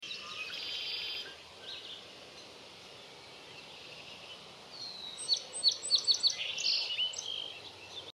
Red Winged Blackbird Is One Of Sound Effects Free Download